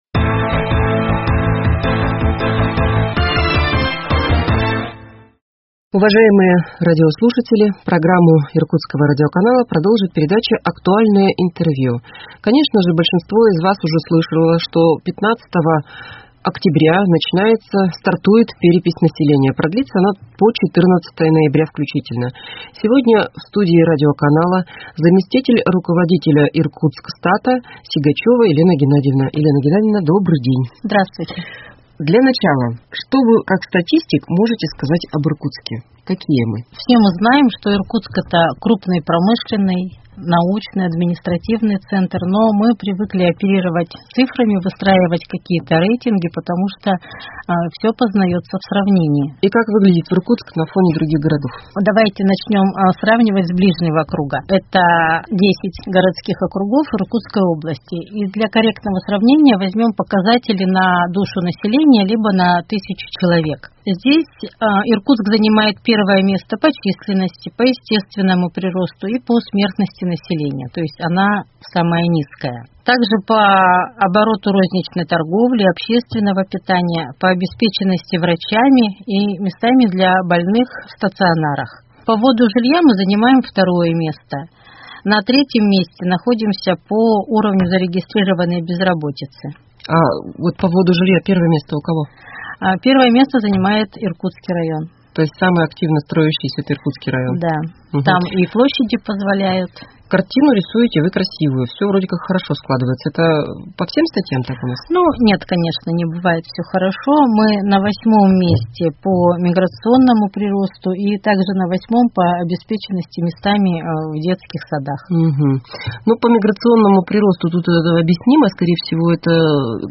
Актуальное интервью: Перепись населения 13.10.2021